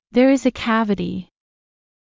ｾﾞｱ ｲｽﾞ ｱ ｷｬﾋﾞﾃｨ